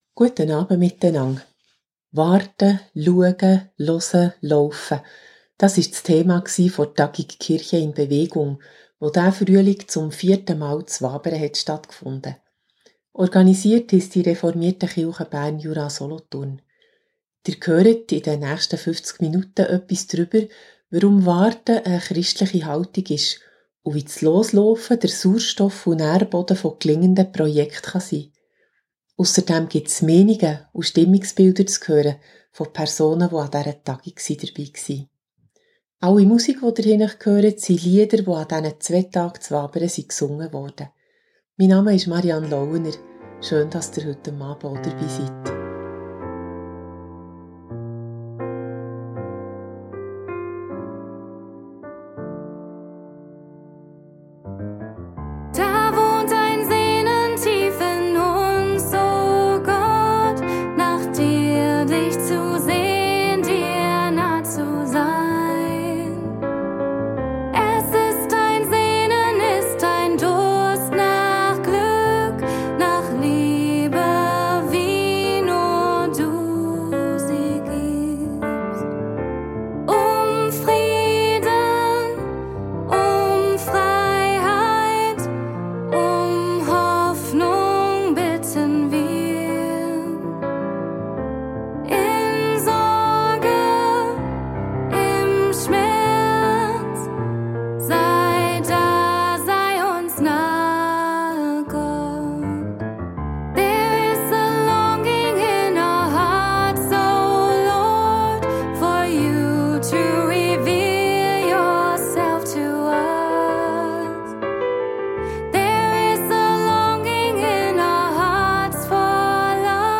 Was ist am Warten christlich und weshalb louft sie nicht endlich drauflos? Ein Bericht über die vierte Tagung «Kirche in Bewegung».